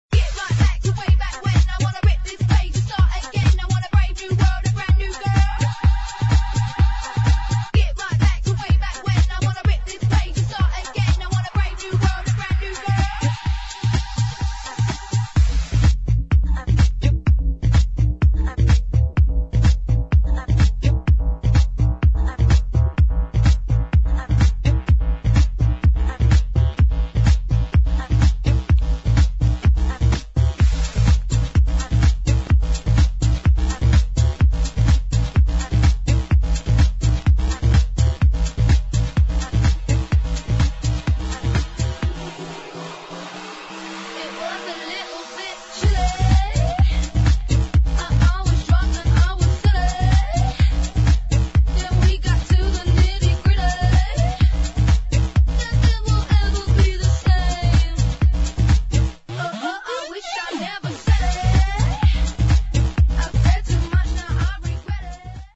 [ HOUSE | ELECTRO ]